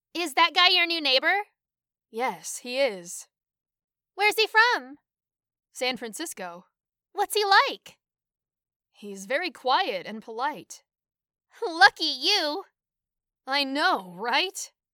会話の音声
ナチュラル
実際に、アメリカ在住のプロの声優が収録した生の音声です。
そして、6つ目のナチュラルスピードですが、これはまさに、アメリカ現地でネイティブ同士が話しているスピードです。
Lesson1_Dialogue_Natural.mp3